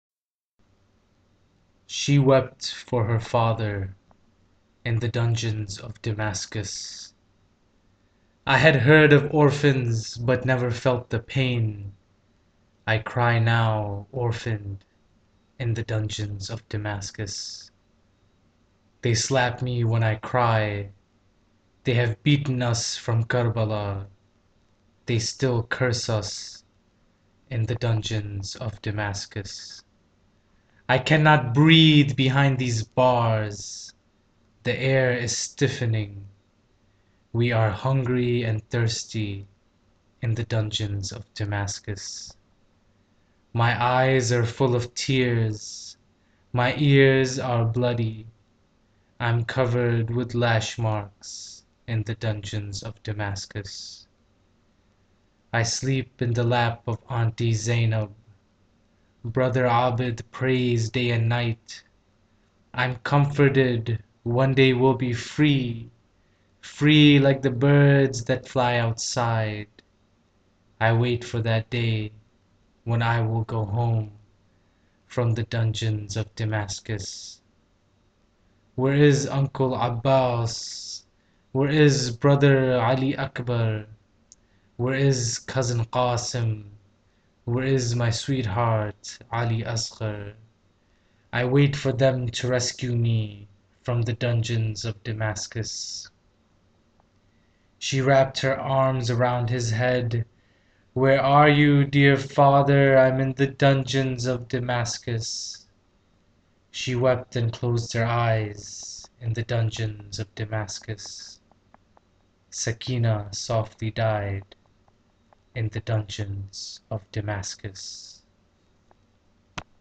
Hear the author reciting Dungeons of Damascus